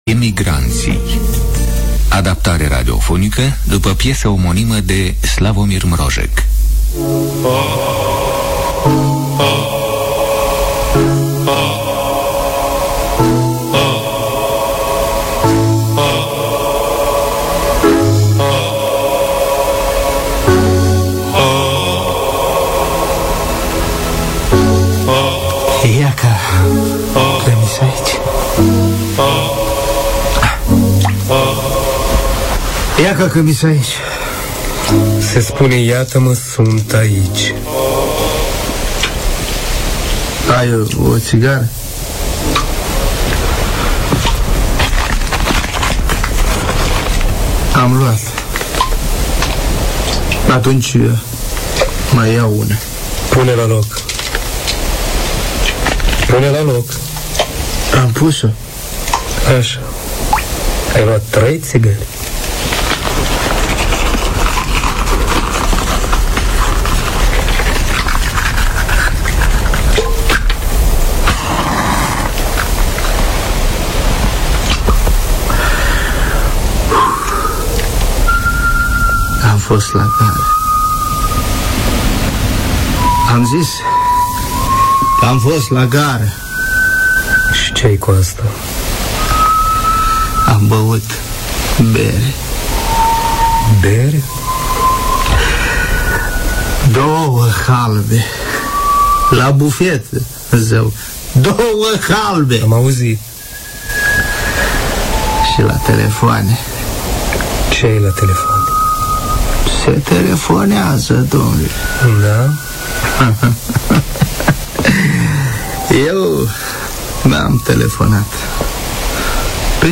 Adaptarea radiofonică
Ilustrația muzicală